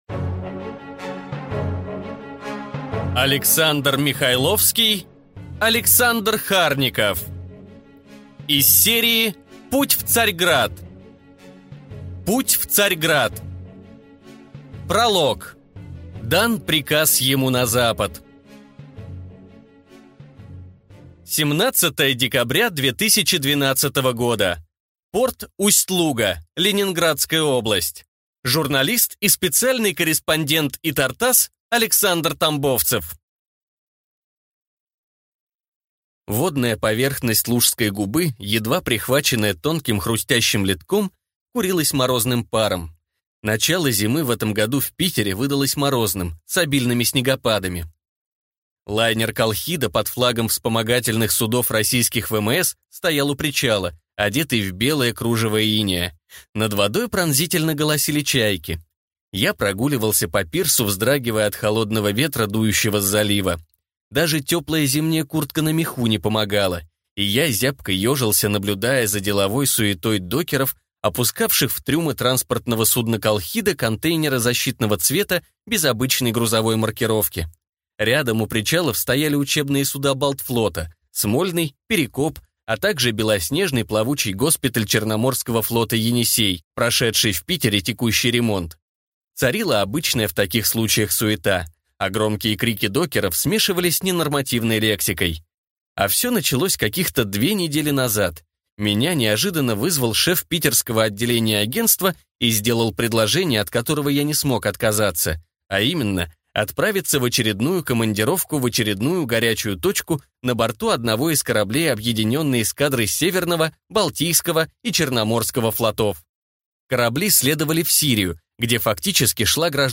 Аудиокнига Путь в Царьград | Библиотека аудиокниг